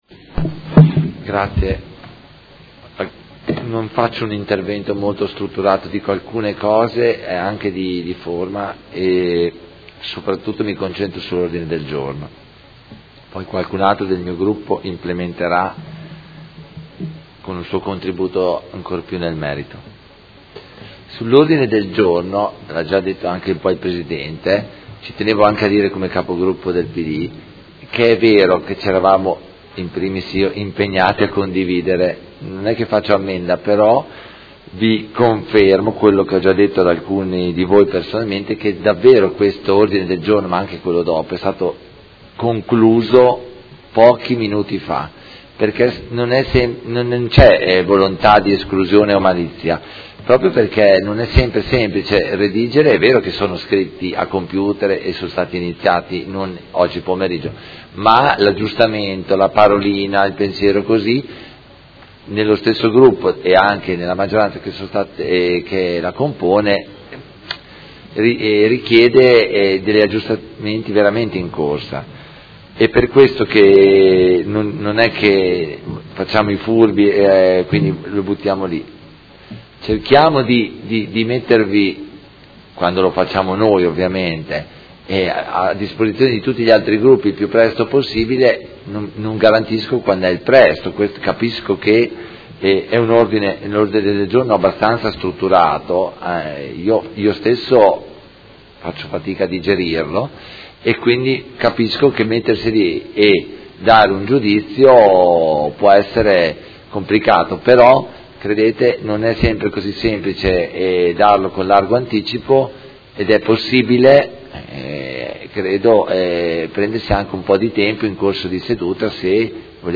Seduta del 26/09/2019. Dibattito su proposta di deliberazione: Recepimento della Deliberazione dell’Assemblea Legislativa della Regione Emilia Romagna del 20.12.2018 n. 186 in materia di disciplina del contributo di costruzione per favorire la riqualificazione e la rigenerazione urbana, e Ordine del Giorno Prot. Gen. n. 282648